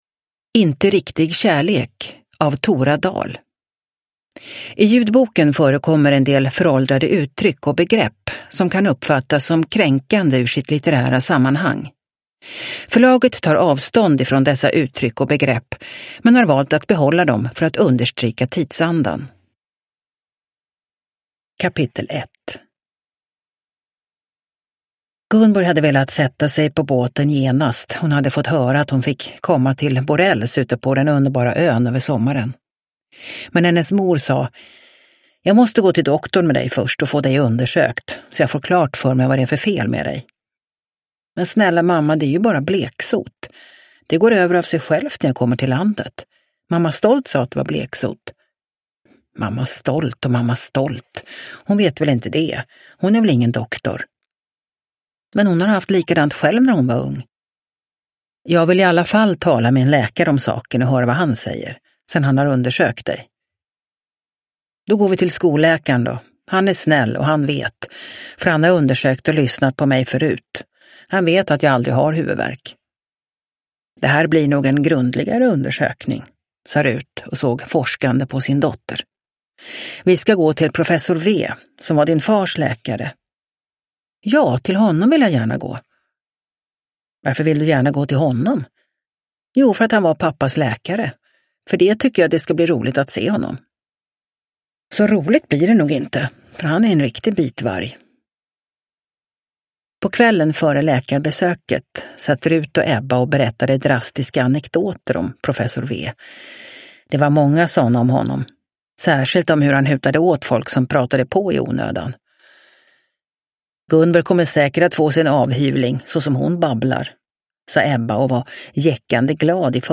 Inte riktig kärlek – Ljudbok – Laddas ner